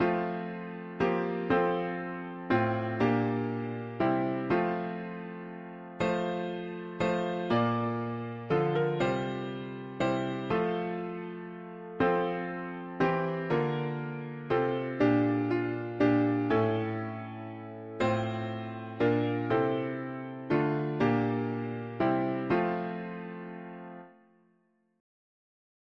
Key: F major
Far more grateful what renews comforts to the poor who call Alternate words Loveliest of trees Tags english secular 4part chords